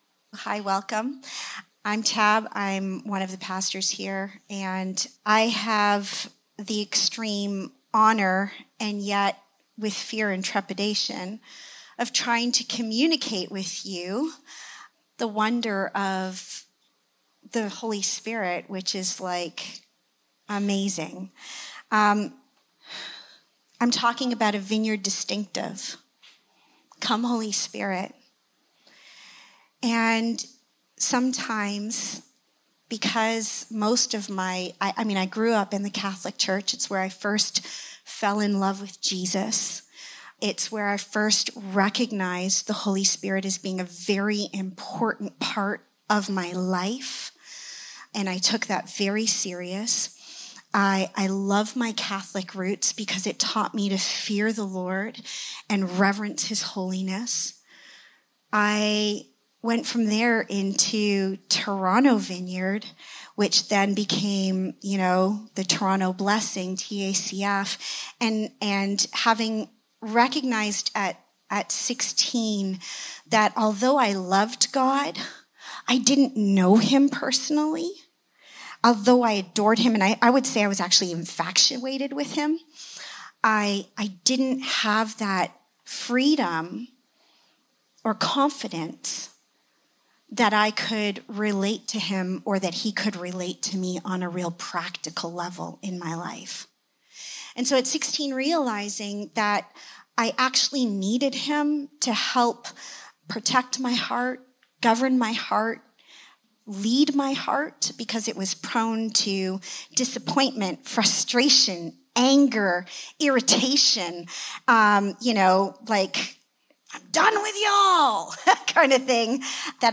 Our Reason for Being Service Type: Sunday Morning Come